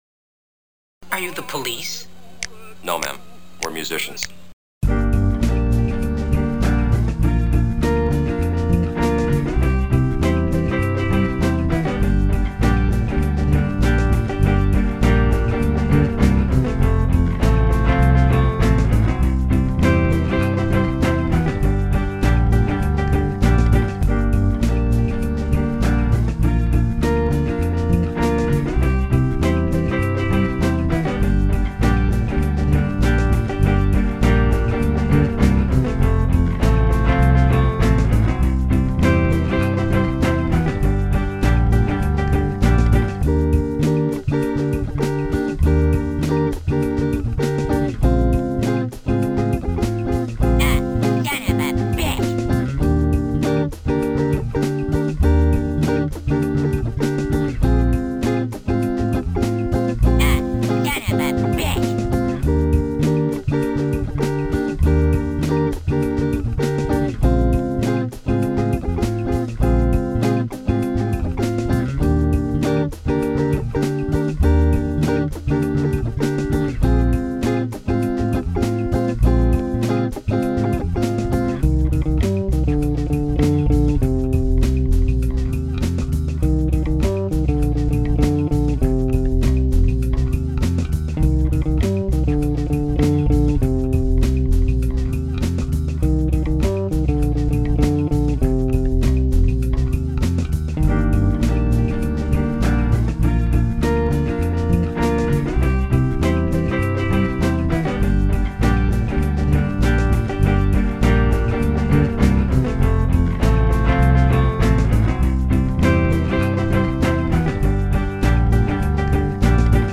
Metal was creeping back into my musicality.